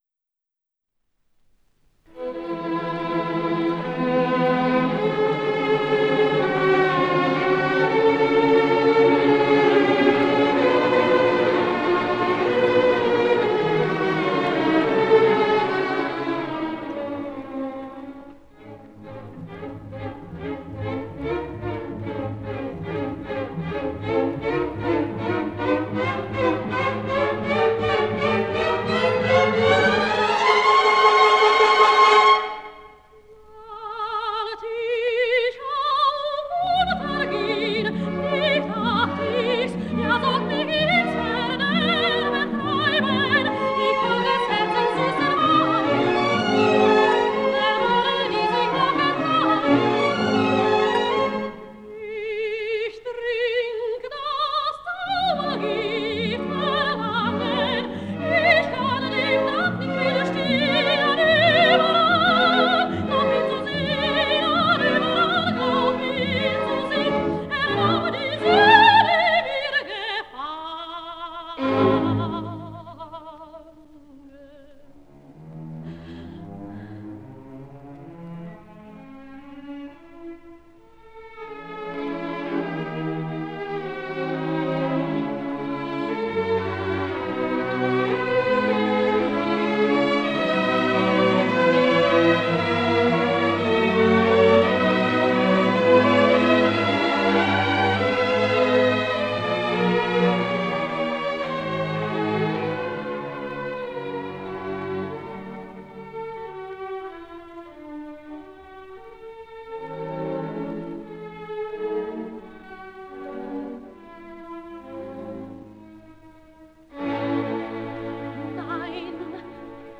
Величкова) (10 июля 1913 - 2 сентября 1996) mdash; болгарская и австрийская оперная певица (сопрано).